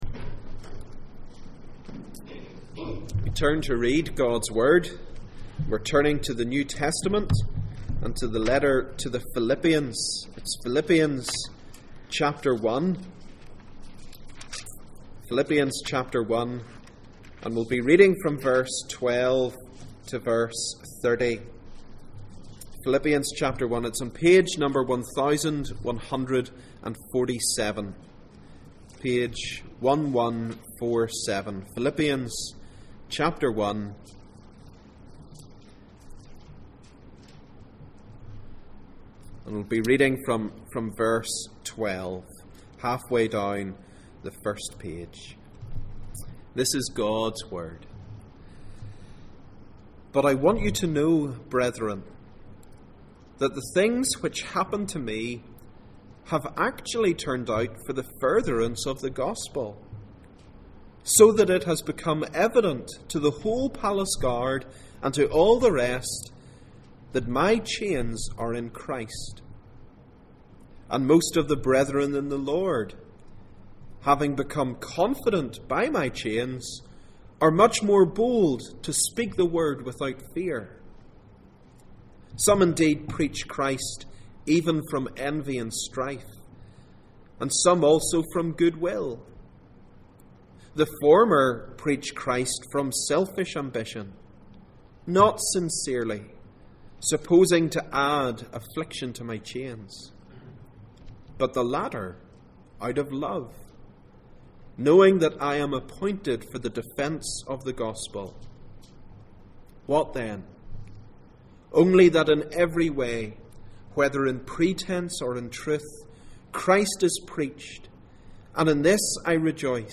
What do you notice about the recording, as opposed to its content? Passage: Philippians 1:12-30 Service Type: Sunday Morning %todo_render% « What’s behind this simple birth?